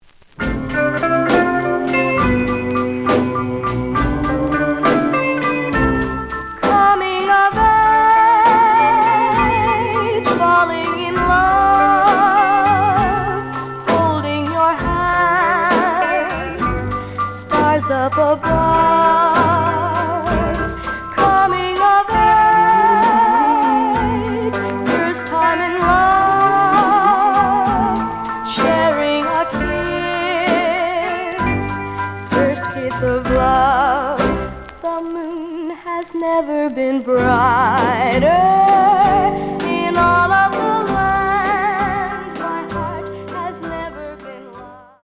The audio sample is recorded from the actual item.
●Genre: Rhythm And Blues / Rock 'n' Roll